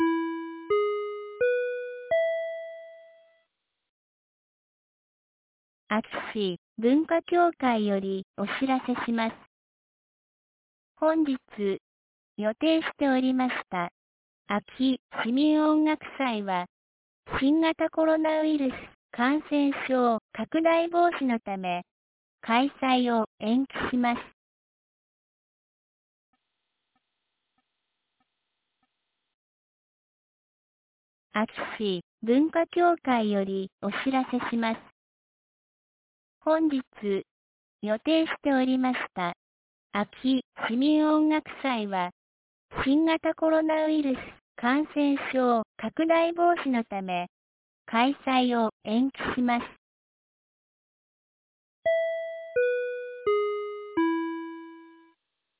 2022年08月21日 09時00分に、安芸市より全地区へ放送がありました。